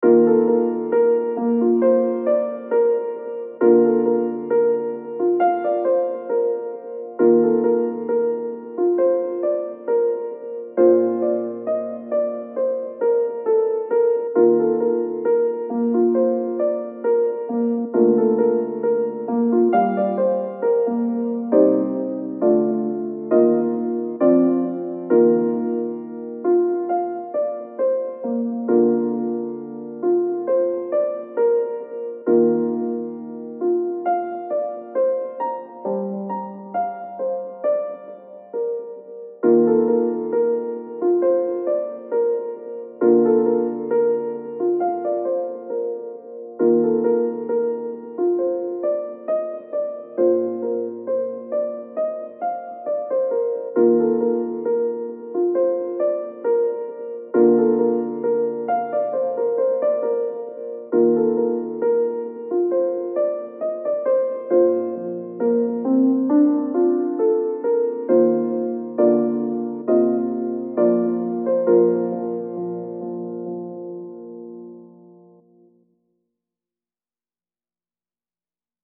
ピアノ 穏やか 青春